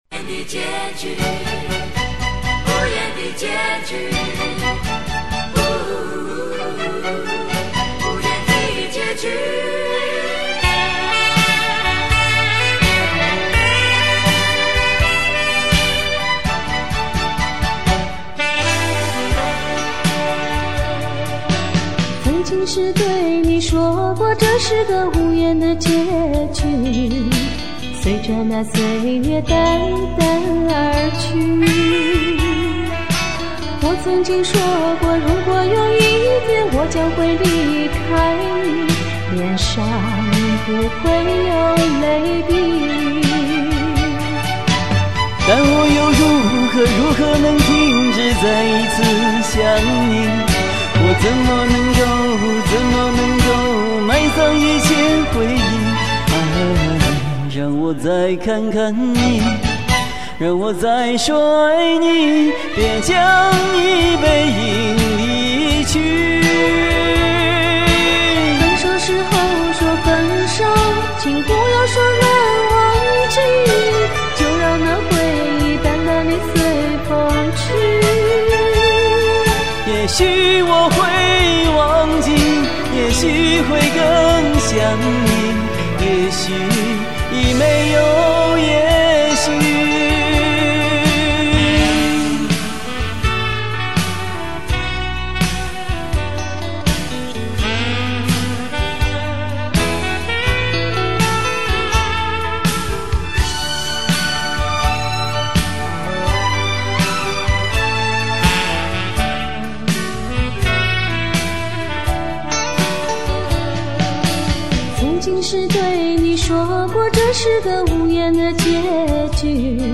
甜美的聲音。